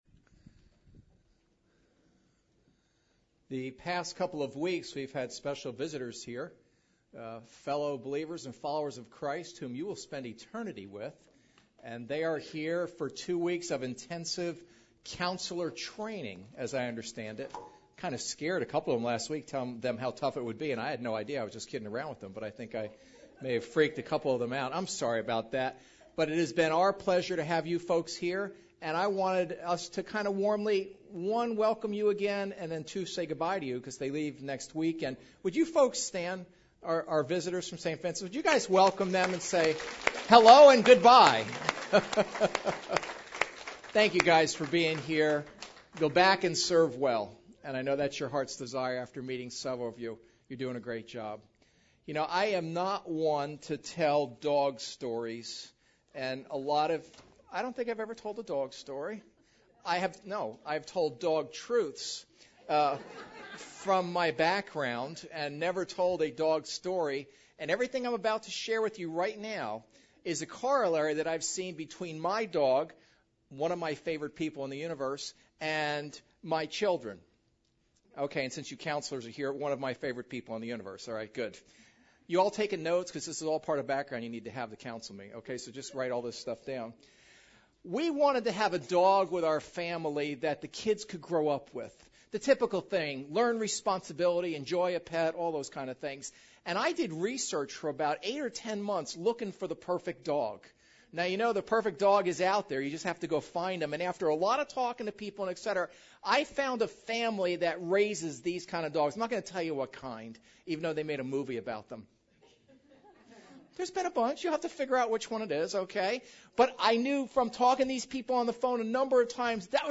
Preacher
Service Type: Sunday Service